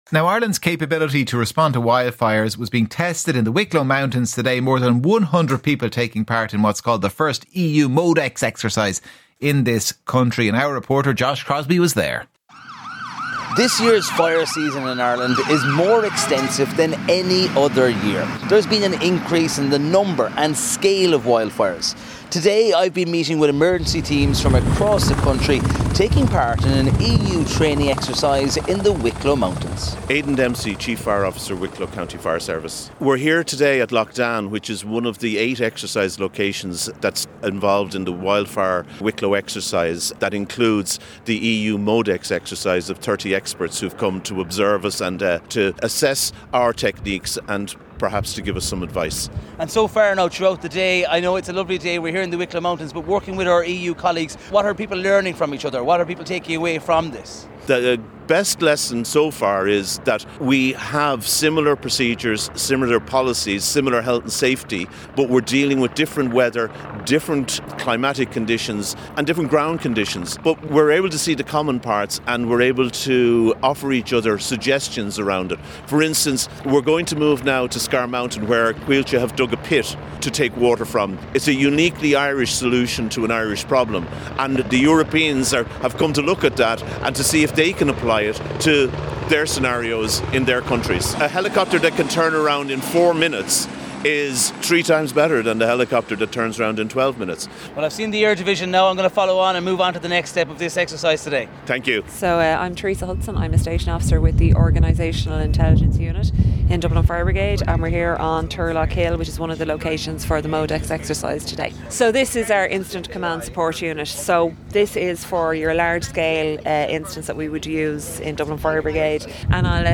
interviews with the people at the centre of the stories